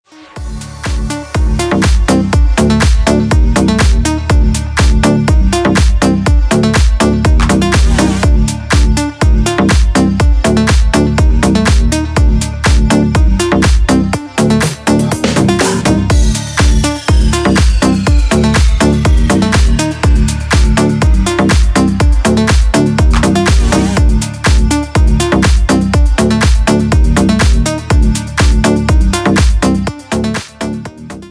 • Качество: 320, Stereo
ритмичные
громкие
deep house
восточные мотивы
без слов
Indie Dance
Без слов, просто интересная мелодия